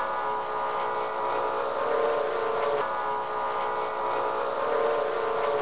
00316_Sound_smallplane.mp3